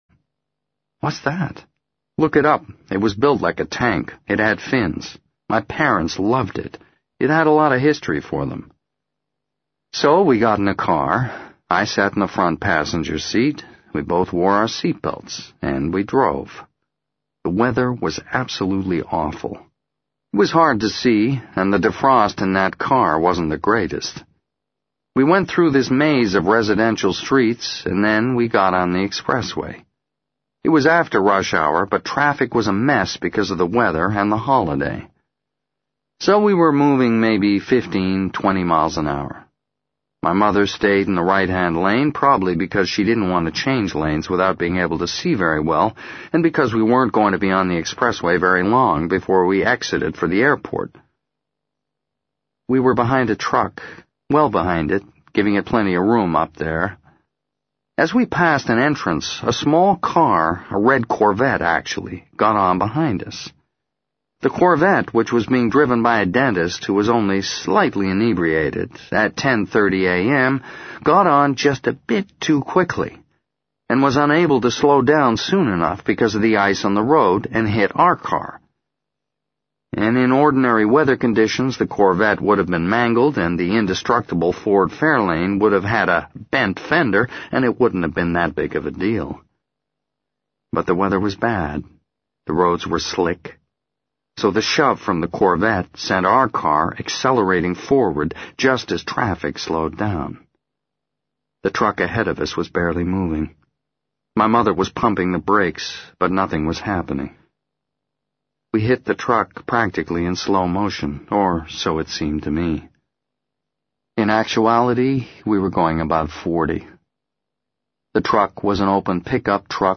在线英语听力室【时间旅行者的妻子】93的听力文件下载,时间旅行者的妻子—双语有声读物—英语听力—听力教程—在线英语听力室